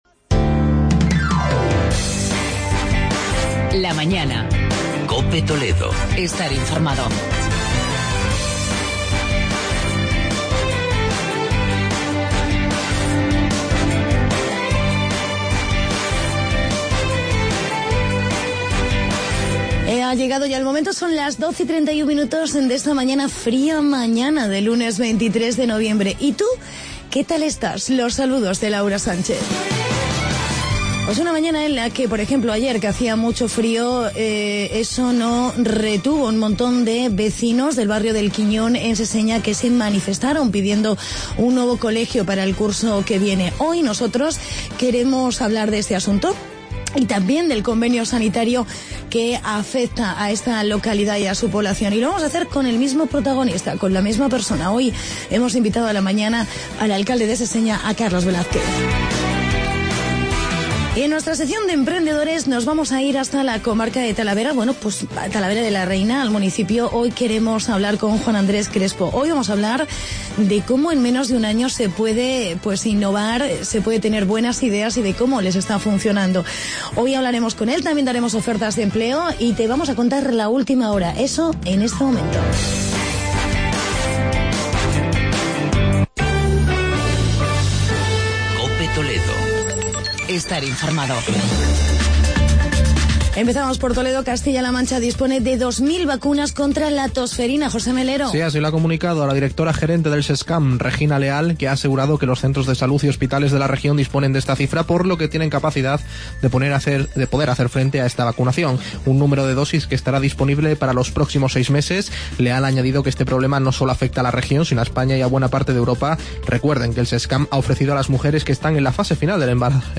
Entrevista con Carlos Velázquez, alcalde de Seseña